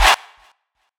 Chant.wav